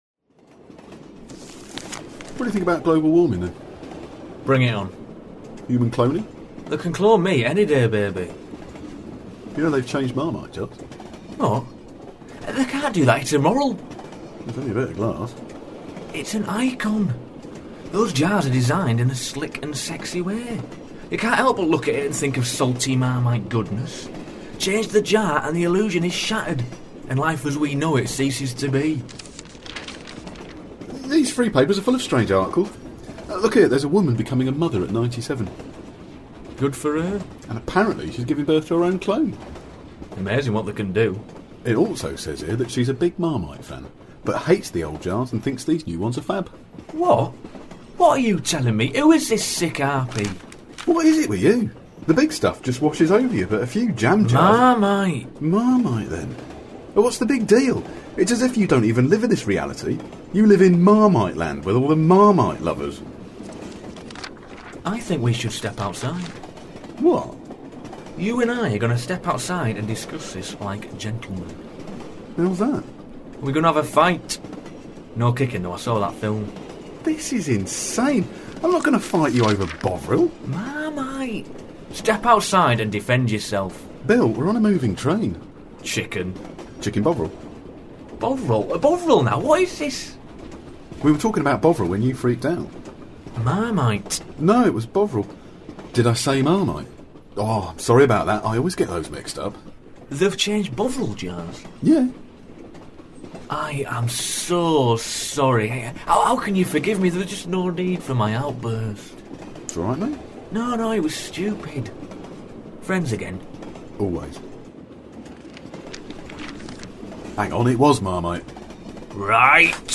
M = Monologue(ish), S = Sketch, P = Short play, CP = Short comedy piece